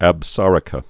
(ăb-särə-kə)